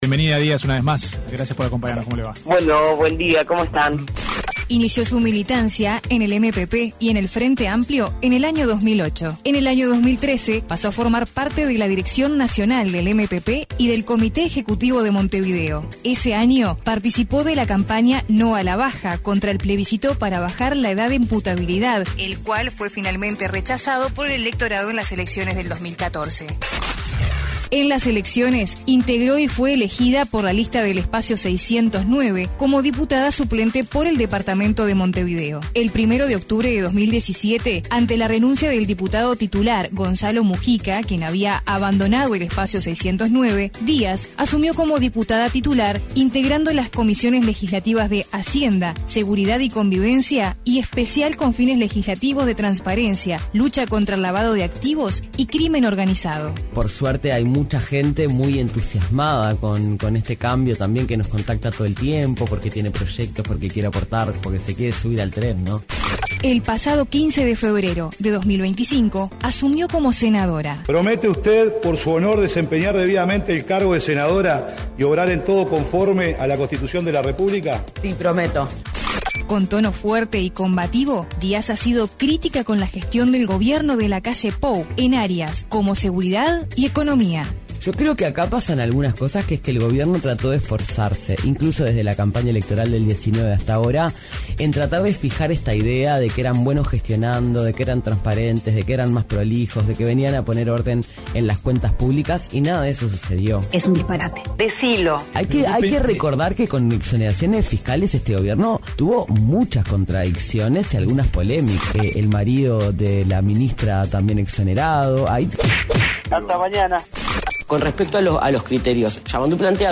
Entrevista a Bettiana Díaz (Senadora del MPP)